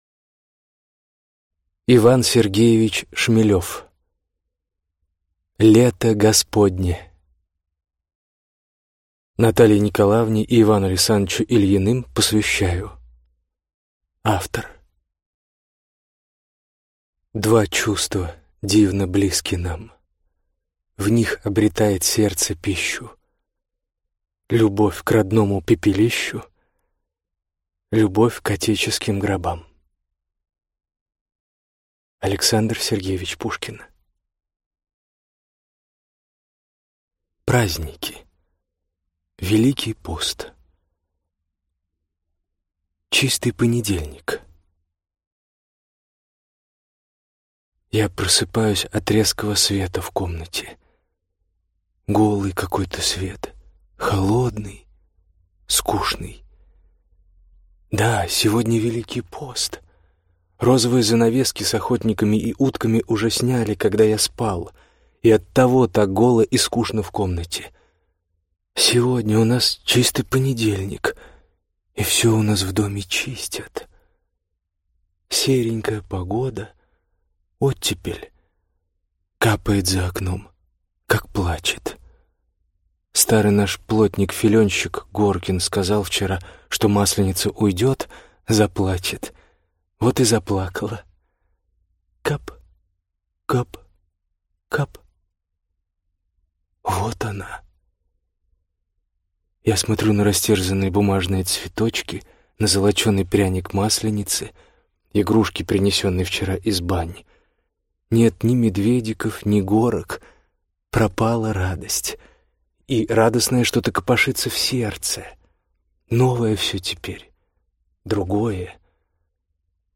Аудиокнига Лето Господне. Праздники | Библиотека аудиокниг